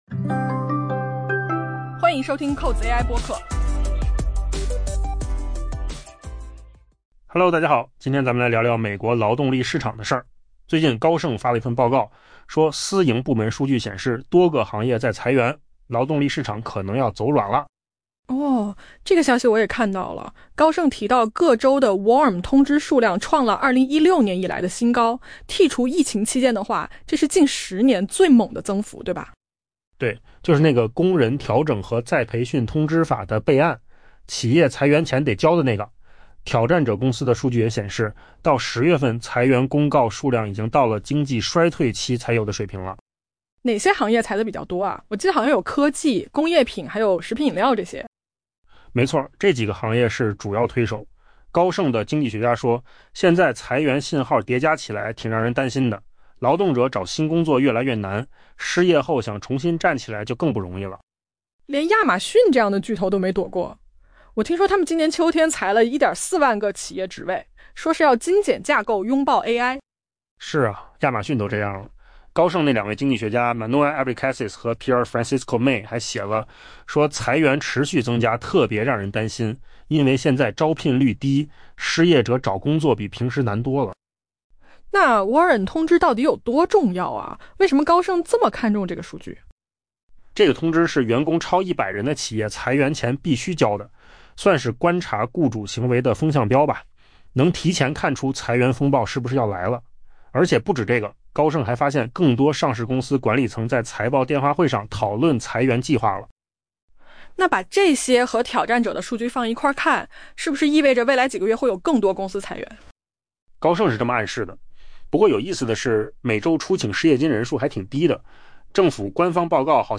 AI 播客：换个方式听新闻 下载 mp3 音频由扣子空间生成 高盛的研究人员在最新发布的一份报告中发出警告，指出随着私营部门数据显示多个行业正掀起一股裁员潮，美国劳动力市场可能正开始走软。